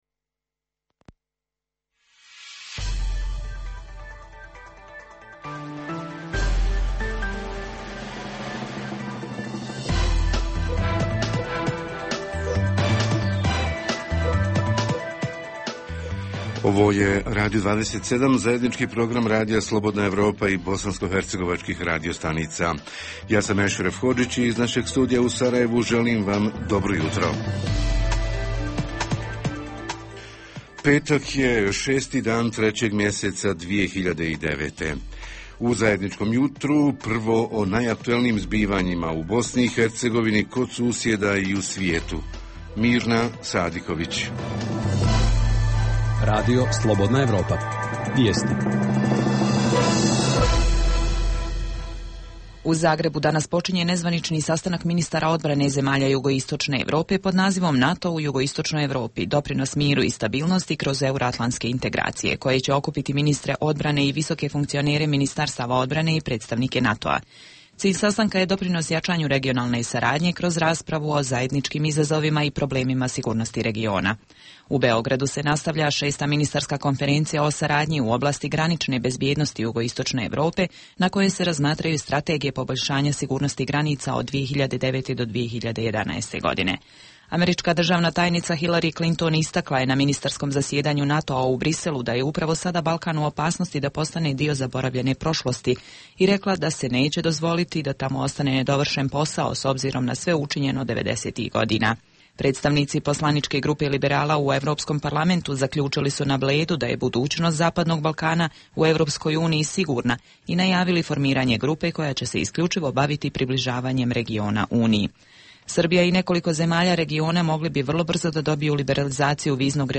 Jutarnji program za BiH koji se emituje uživo danas ima za temu: stanje u bh. privredi i životni standard građana BiH prvih njeseci ove godine - kako preživjeti krizu?
Redovni sadržaji jutarnjeg programa za BiH su i vijesti i muzika.